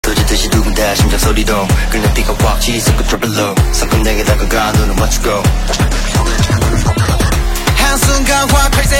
KSHMR_Vocal_Words_-_Hey_Ho_Crowd_Chant